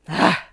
vs_fbrekkex_sick.wav